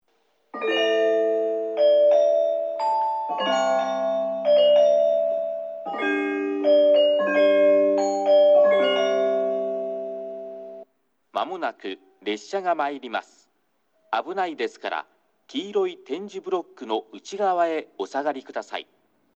2番のりば接近放送　男声